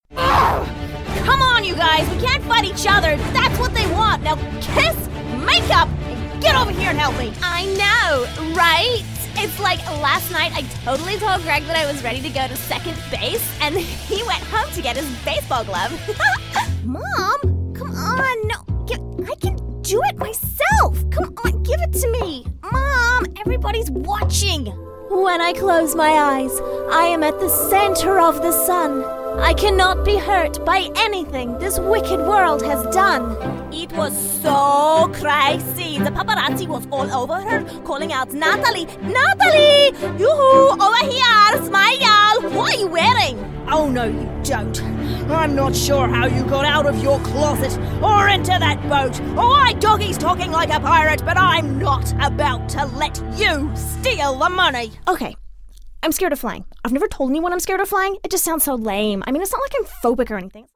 20s-30s. Female. New Zealand.